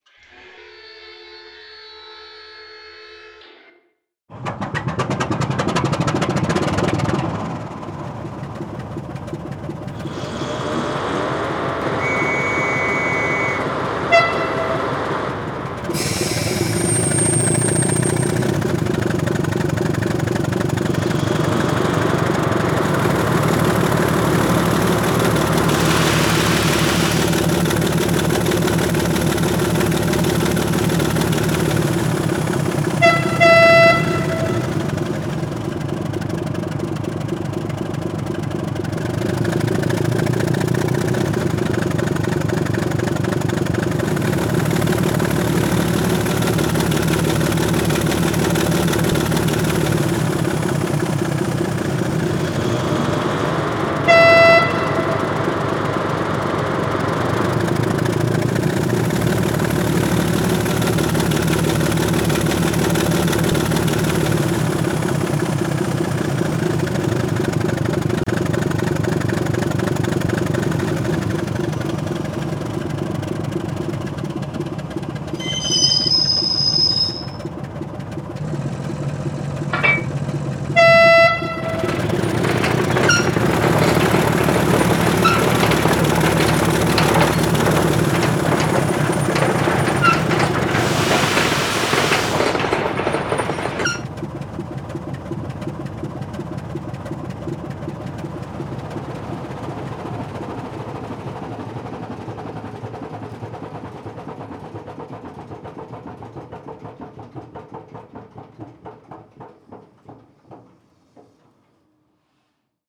Renfe Serie 307 (Renfe Serie 10700) diesel-
Renfe_307.mp3